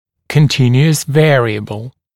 [kən’tɪnjuəs ‘veərɪəbl][кэн’тинйуэс ‘вэариэбл]постоянно меняющаяся величина